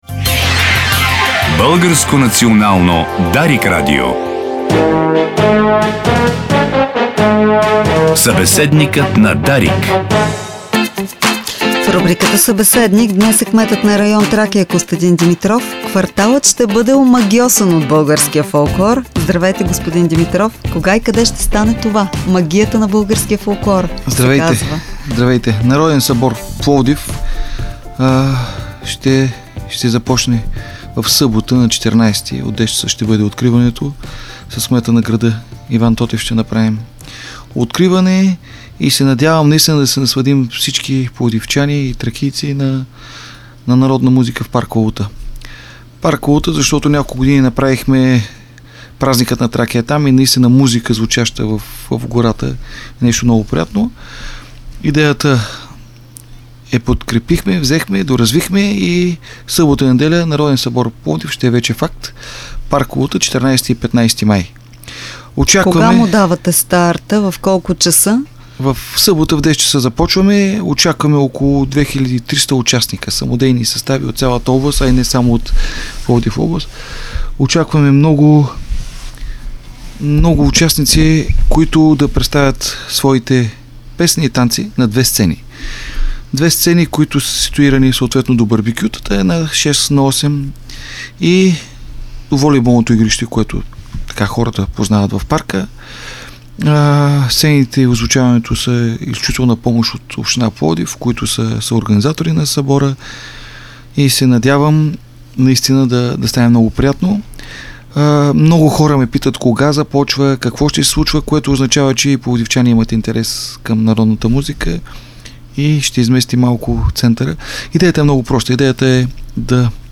Това съобщи в ефира на Дарик кметът на квартала Костадин Димитров.